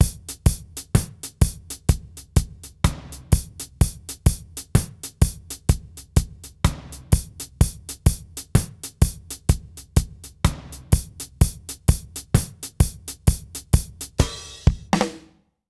British REGGAE Loop 132BPM - 2.wav